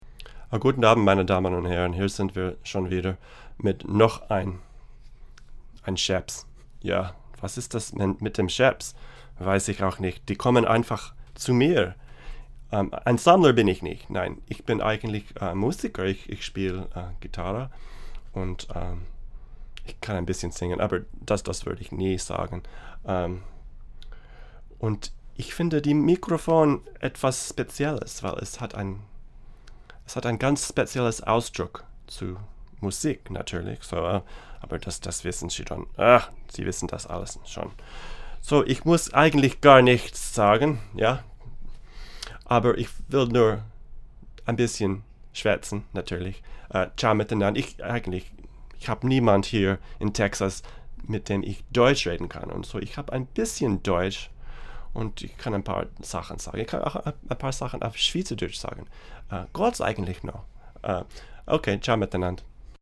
Up for consideration is a stereo pair of Schoeps MK-2 omnidirectional microphone capsules.
Here are sixteen quick, 1-take MP3 sound files showing how the capsules sound using a CMC 6 body (see other ongoing auction) through a Presonus ADL 600 preamp into a Rosetta 200 A/D converter. No EQ or effects.
VOICE OVER:
VOGerman.mp3